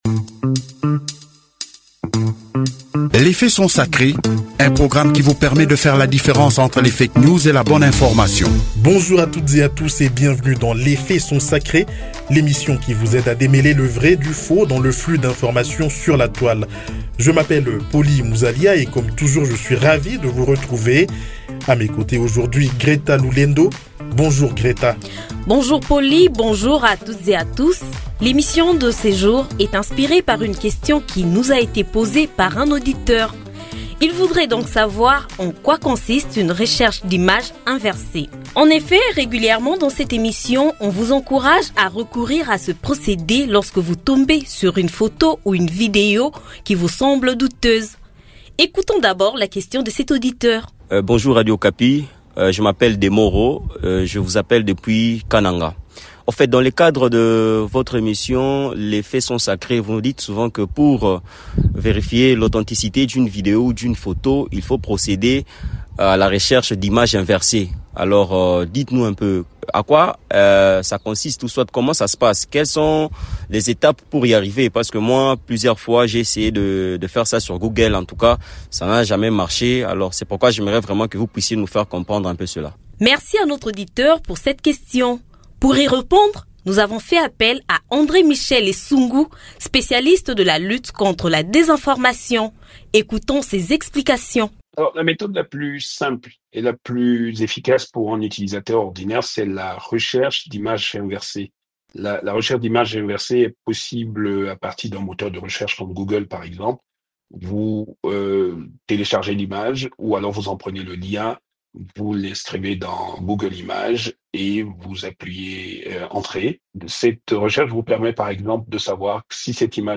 spécialiste de la lutte contre la désinformation nous l'explique en détail.